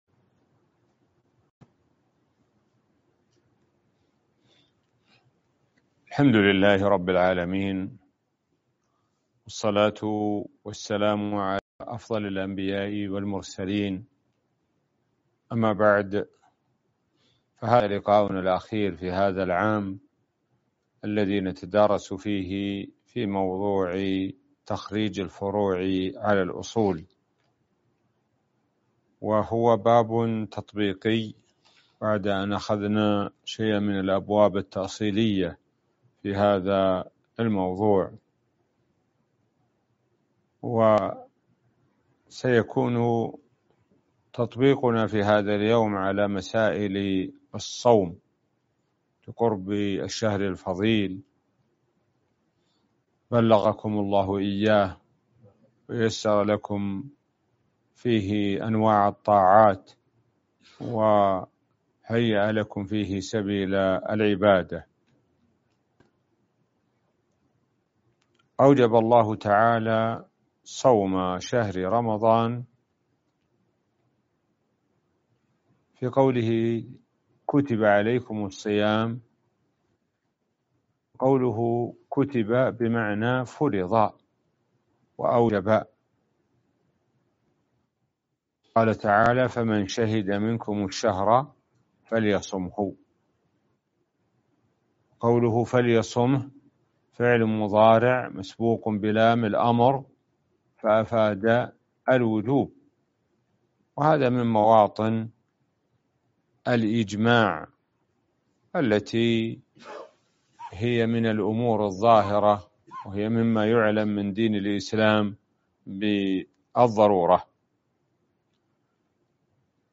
الموقع الرسمي لفضيلة الشيخ الدكتور سعد بن ناصر الشثرى | مسائل في تخريج الفروع على الأصول- الدرس (9)